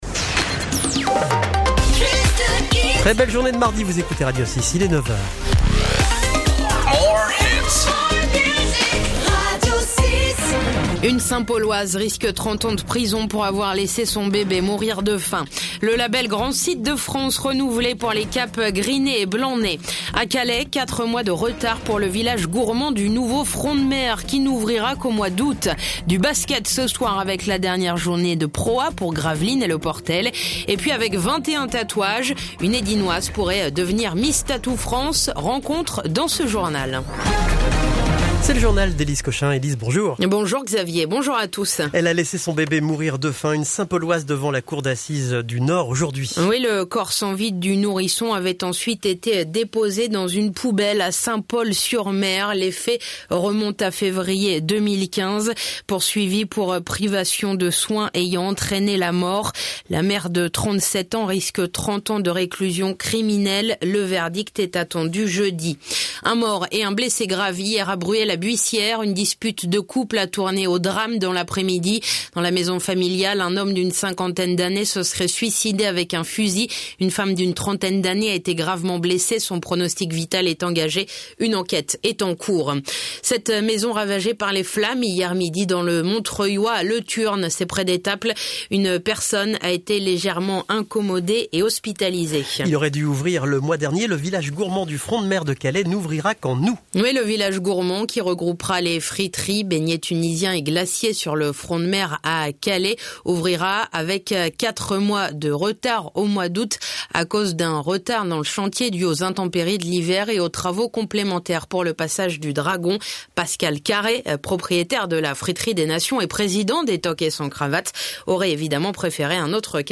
Le journal Côte d'opale du mardi 15 mai